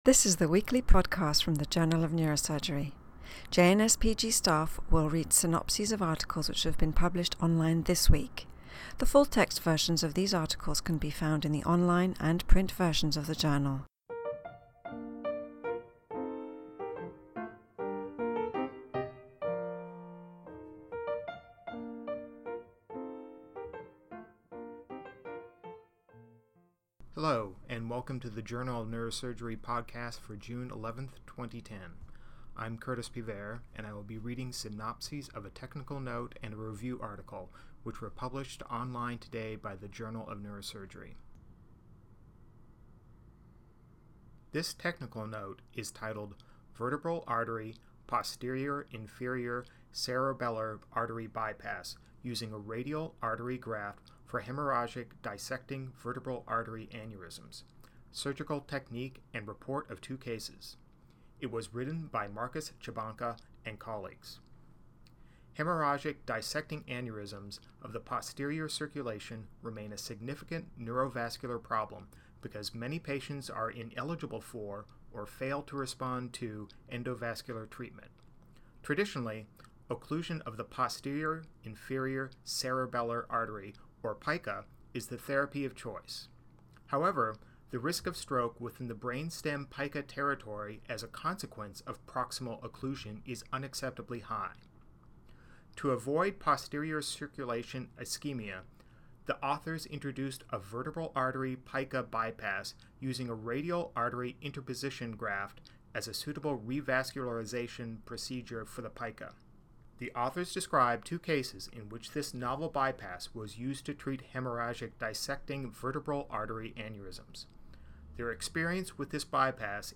reads synopses of Journal of Neurosurgery articles published online on June 11, 2010.